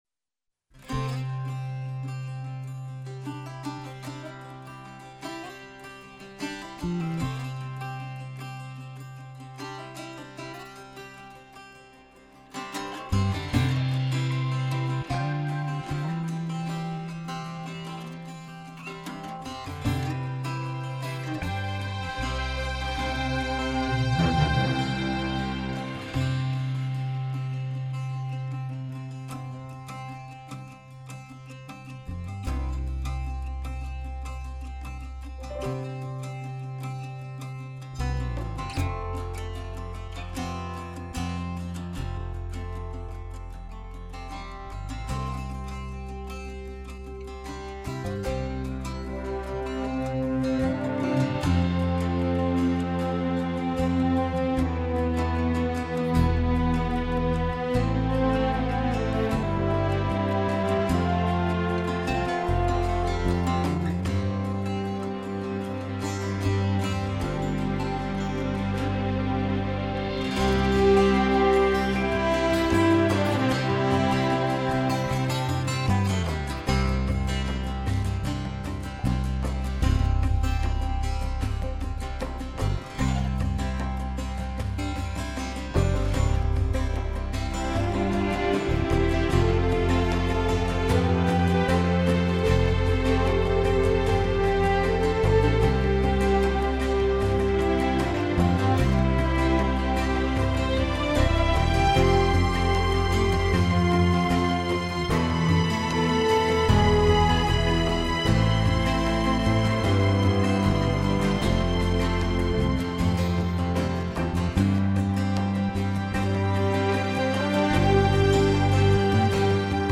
до мажор